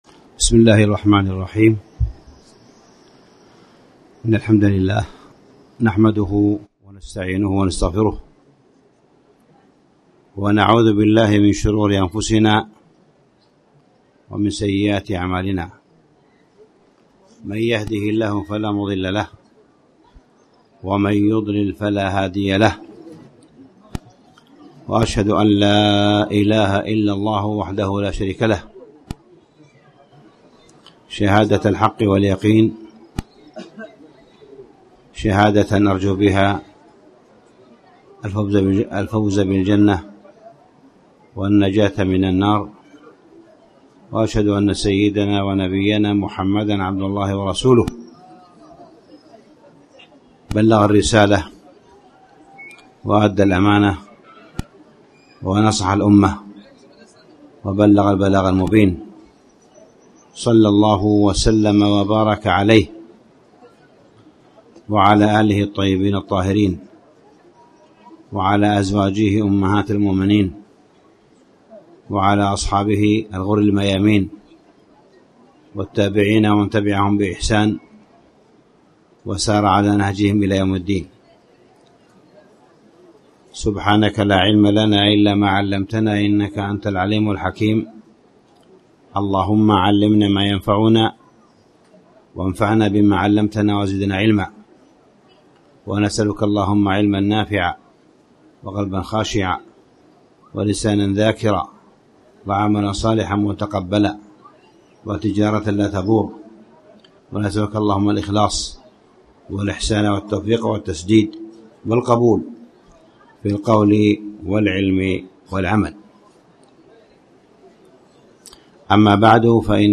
تاريخ النشر ٢٩ محرم ١٤٣٩ المكان: المسجد الحرام الشيخ: معالي الشيخ أ.د. صالح بن عبدالله بن حميد معالي الشيخ أ.د. صالح بن عبدالله بن حميد الوسطية والاعتدال
29محرم-محاضرة-الوسطية-والأعتدال.mp3